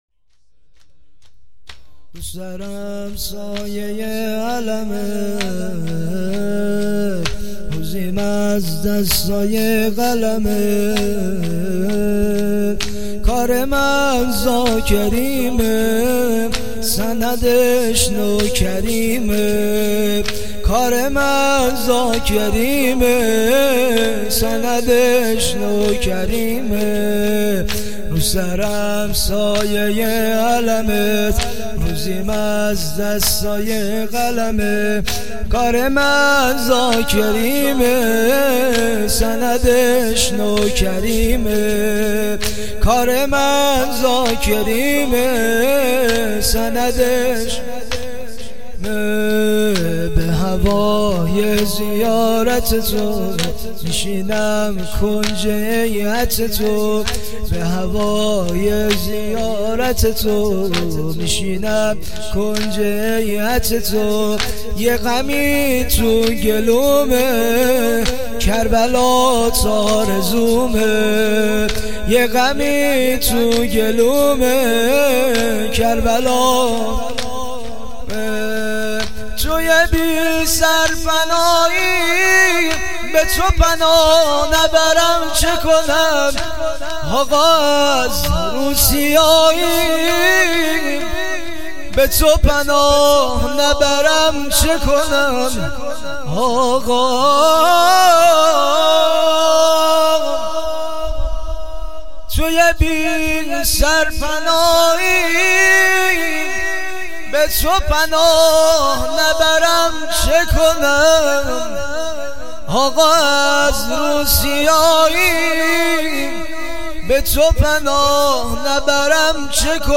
🏴مراسم هفتگی 🔸پنجشنبه۲۱تیر ۹۷